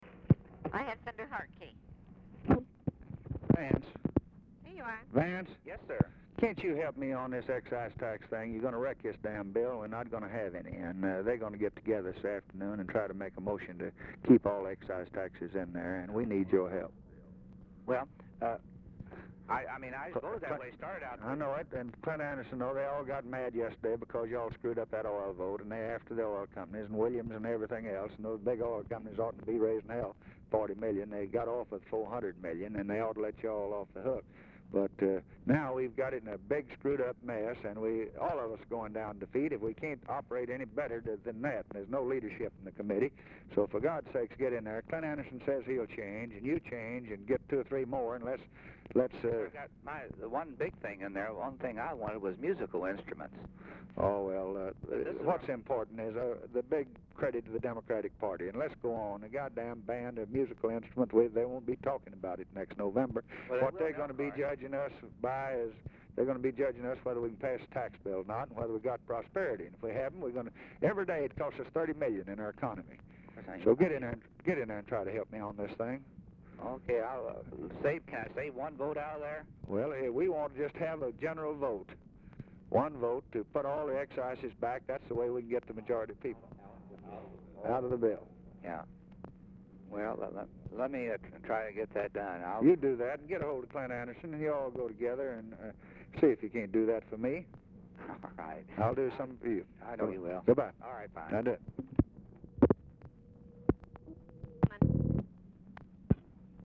Telephone conversation # 1492, sound recording, LBJ and VANCE HARTKE
Format Dictation belt
Location Of Speaker 1 Oval Office or unknown location